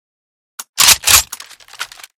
unjam_empty.ogg